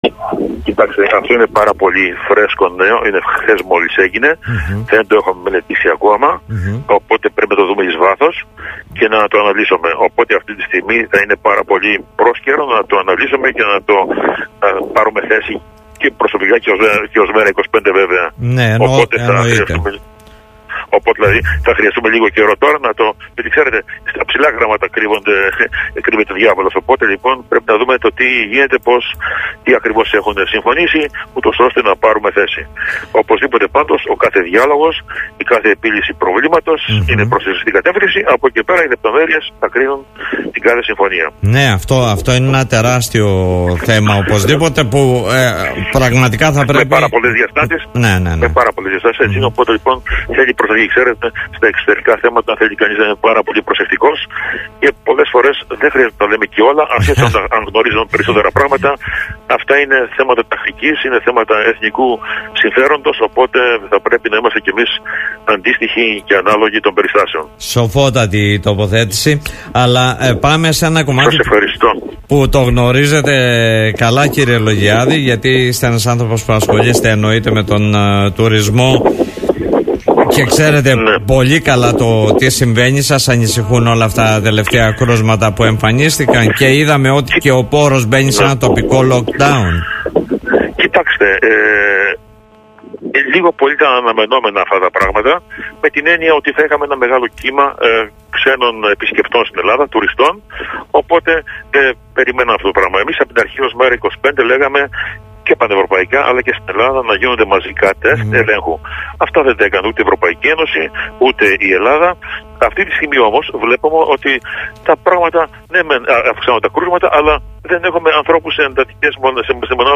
Ακούστε τον κ. Λογιάδη: